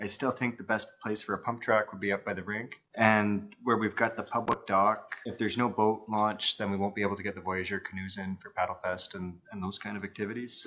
Councillor Chris McGuire likes how most of the park is laid out but reiterated his previous concerns about the blueprint.